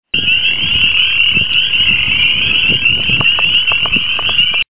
Western Chorus Frog Pseudacris triseriata HEAR THIS FROG'S CALL
wchoruscall.mp3